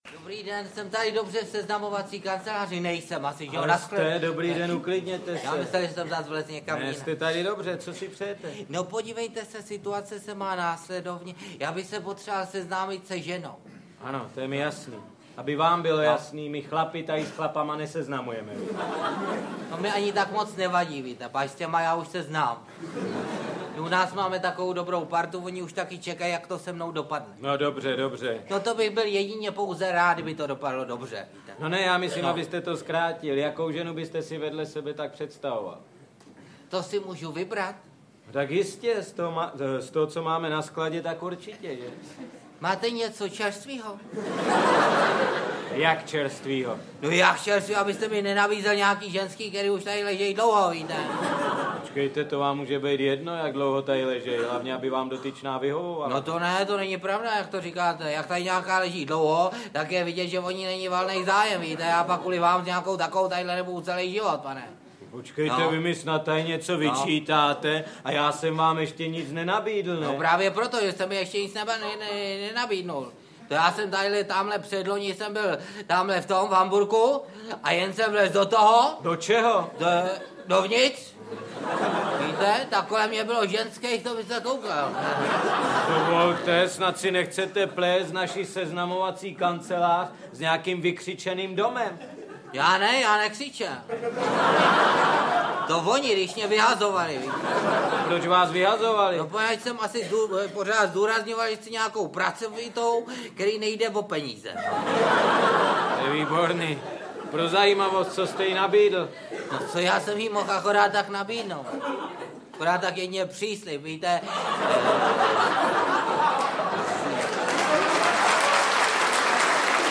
Slavná humoristická čísla komiků, kteří společně bavili diváky na scéně divadla Semafor. Výběr z dnes již legendárních výstupů zaznamenává skeče z let 1957-89
Audio kniha
• InterpretPetr Nárožný, Jiří Krampol, Miloslav Šimek, Luděk Sobota, Uršula Kluková, Josef Fousek